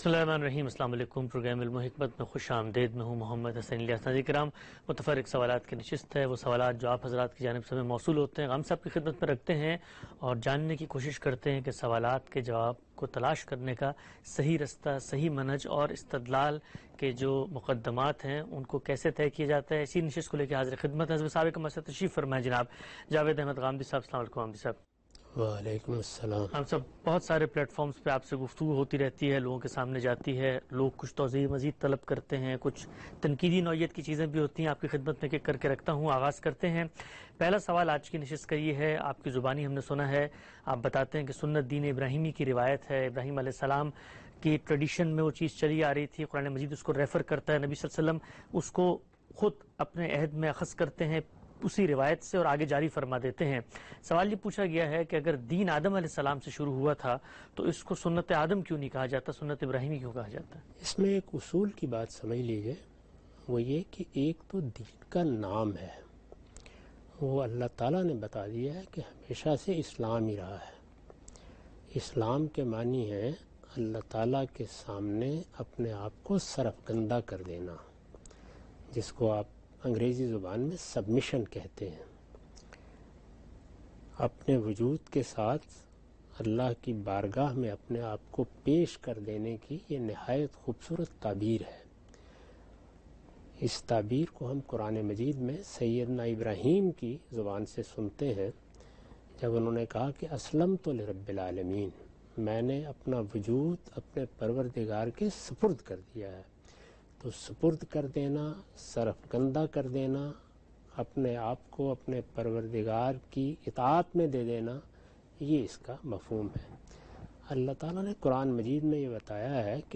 In this program Javed Ahmad Ghamidi answers the questions of different topics in program "Ilm-o-Hikmat".